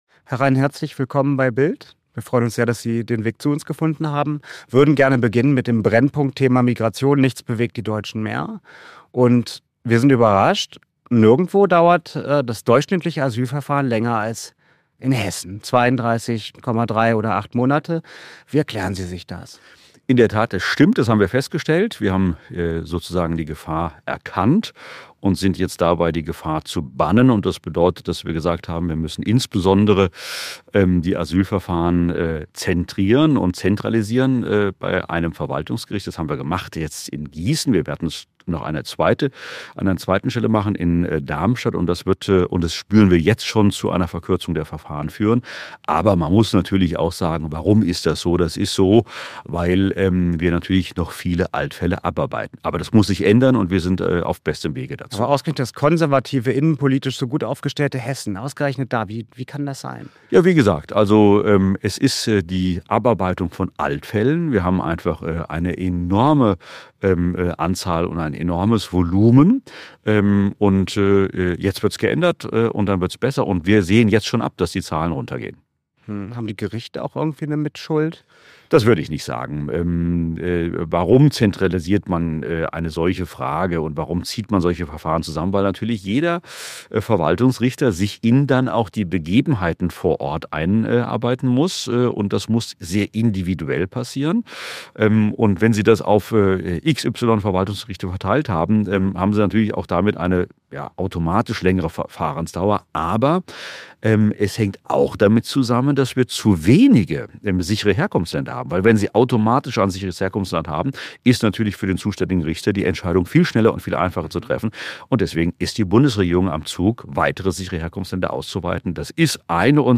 Rhein im großen Interview mit BILD über die Migrationskrise und die Frage, wer Kanzlerkandidat der CDU/CSU werden soll.